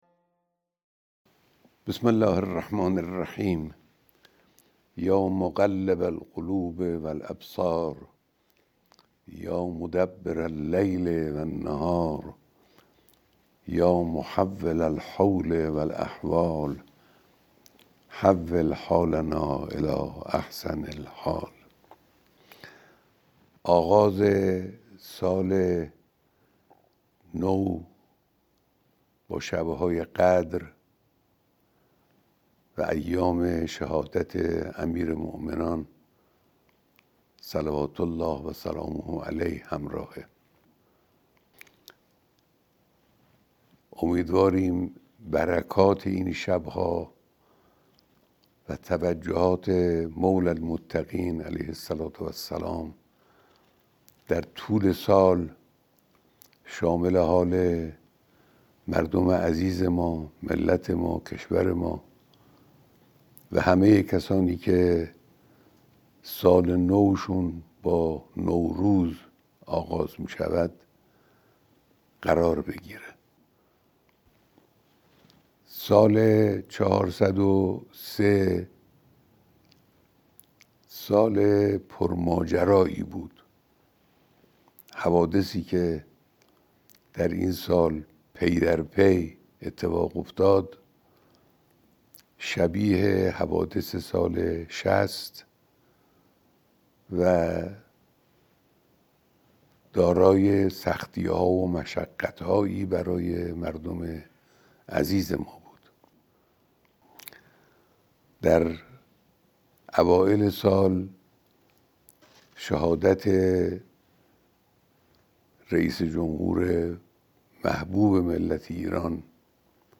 پیام رهبر معظم انقلاب اسلامی به مناسبت حلول سال نو